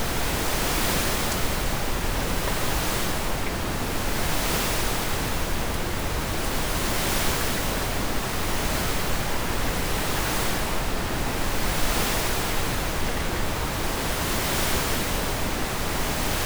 Ocean.wav